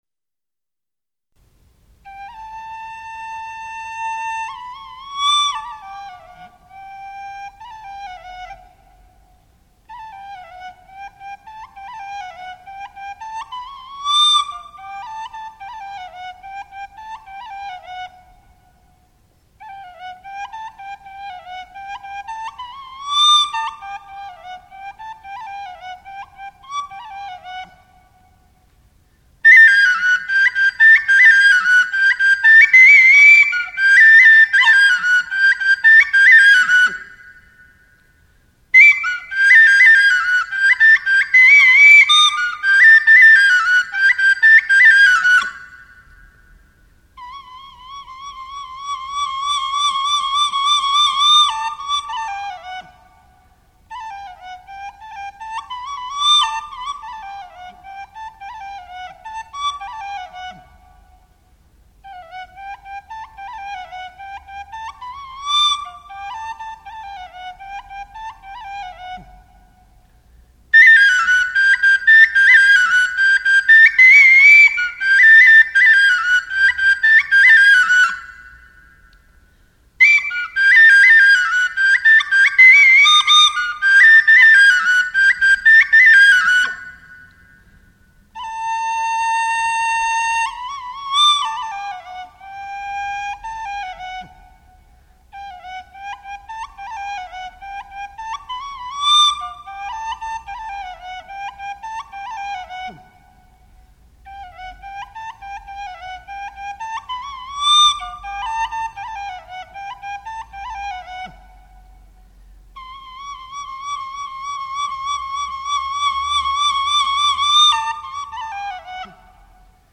洞经古乐自明清以来即风行于中国各地文化阶层，因其主要用于谈演道教经典《玉清无极总真文昌大洞仙经》（简称洞经）而得名，集汉族民间小调、道教音乐、佛教音乐和宫廷音乐之大成，是明清时期传入丽江的道教古乐，带有汉曲丝竹乐风。